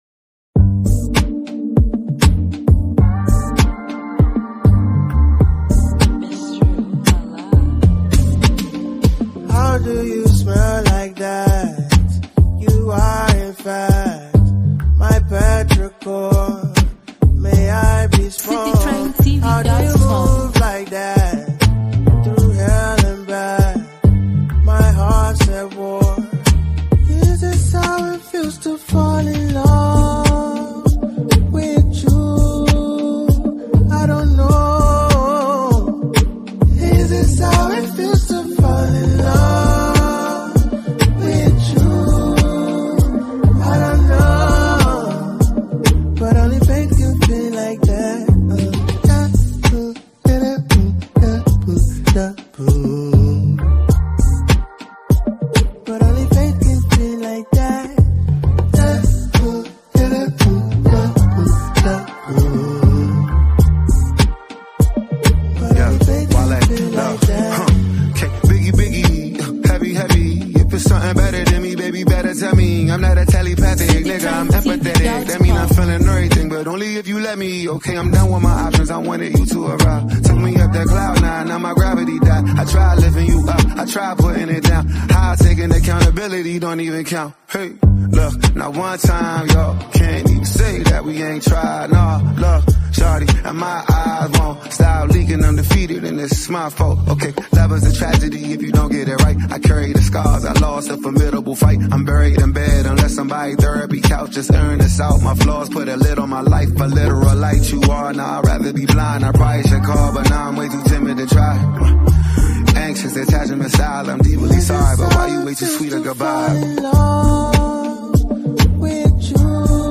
a smooth and introspective track